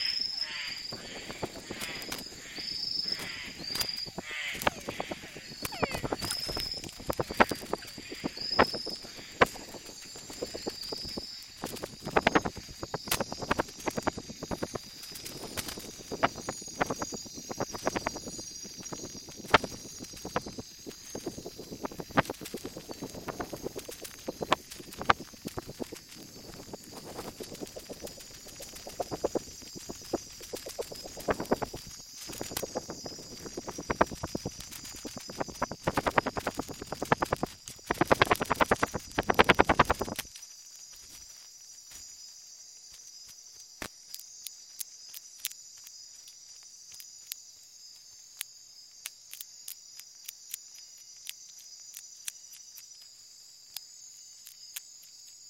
At the same time, the audio trace fills with a rapid series of ultrasonic echolocation calls, the staccato sound of a hunter steering through darkness. In my ears, a rush of air surges past the tiny microphone, then there’s a splash, more wingbeats, and finally the faint, wet crunching of teeth.
Biologger recording of a bat catching a frog from flight.